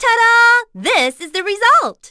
Juno-Vox_Skill4.wav